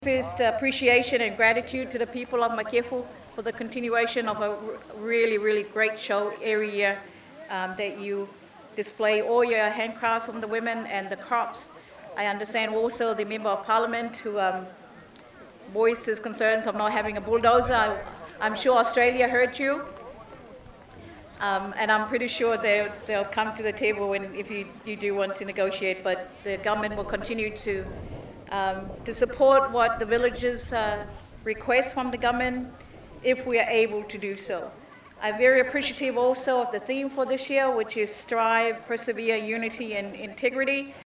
Natural Resources Minister-Mona Ainu’u